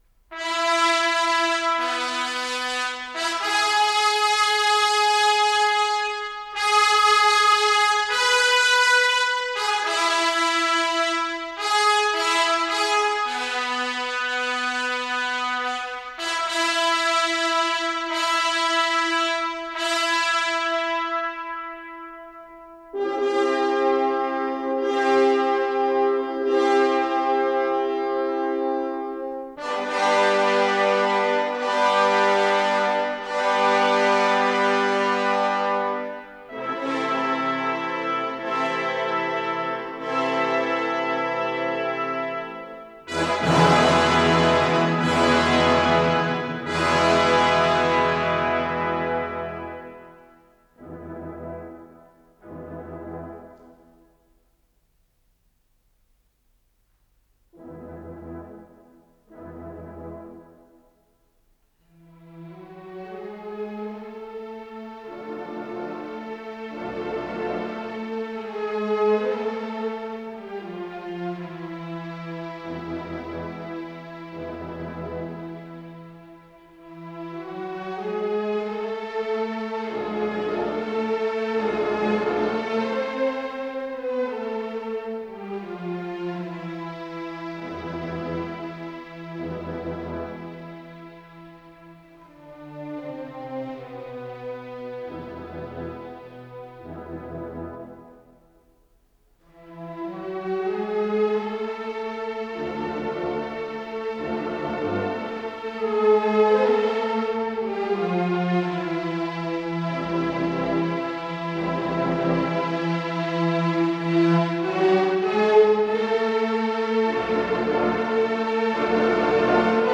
Исполнитель: Большой симфонический оркестр Всесоюзного радио и Центрального телевидения